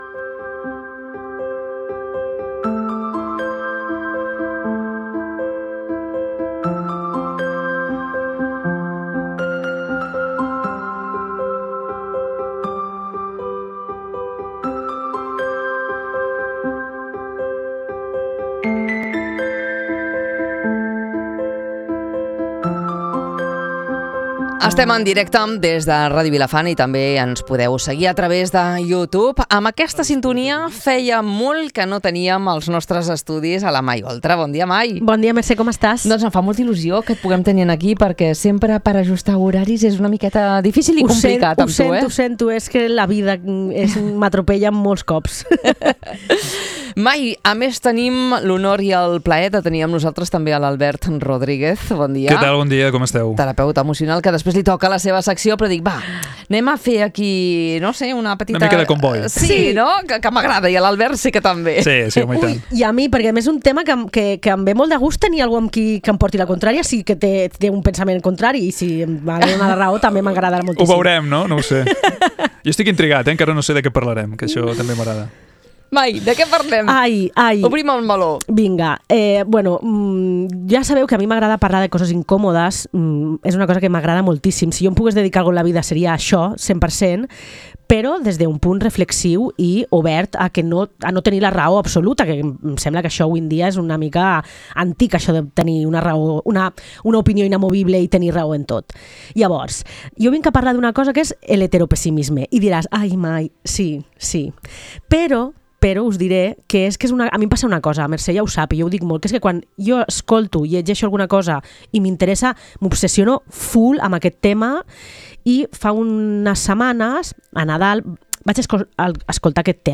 Una conversa per qüestionar rols, mites i dinàmiques que sovint es donen per normals.